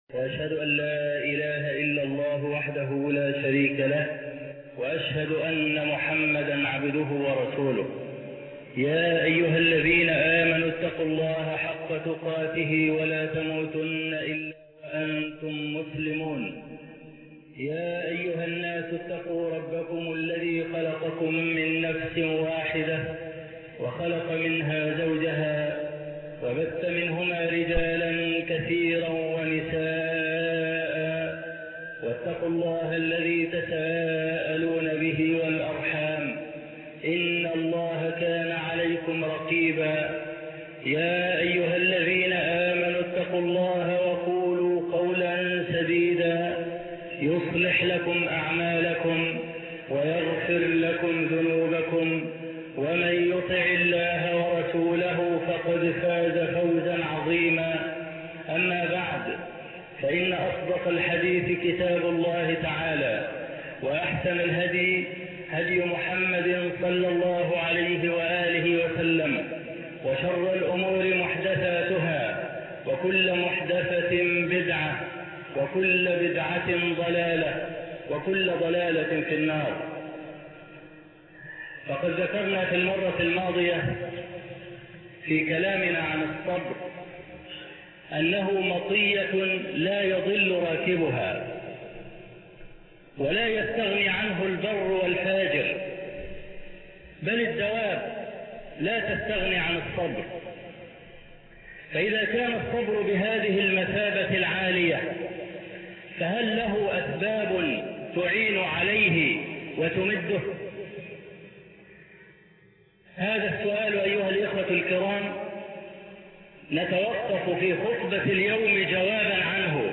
الأسباب المعينة على الصبر (1) خطبة نادرة - الشيخ أبو إسحاق الحويني